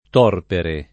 torpere [ t 0 rpere ]